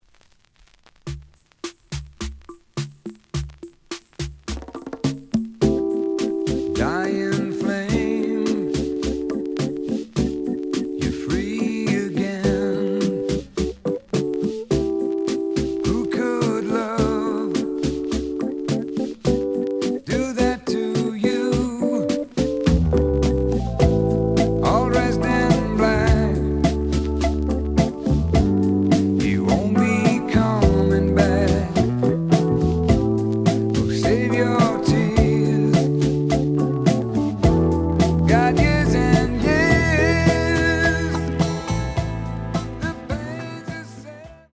The audio sample is recorded from the actual item.
●Genre: Rock / Pop
Looks good, but slight noise on A side.